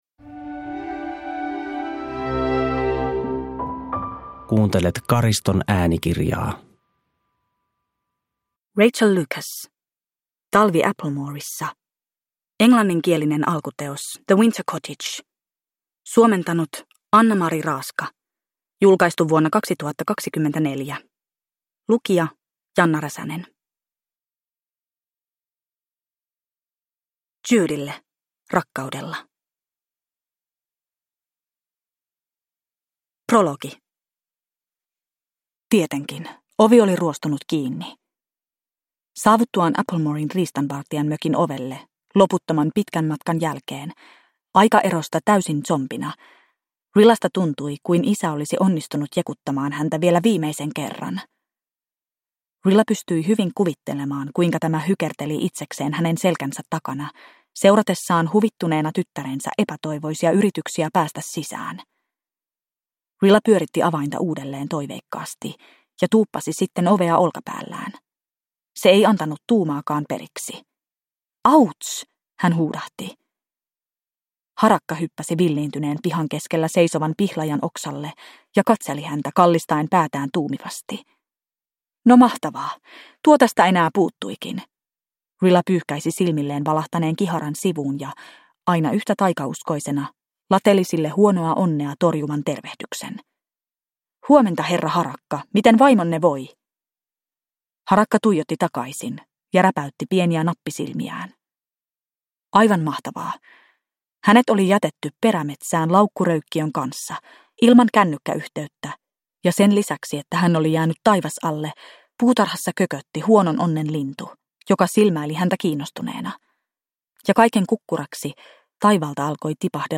Talvi Applemoressa (ljudbok) av Rachael Lucas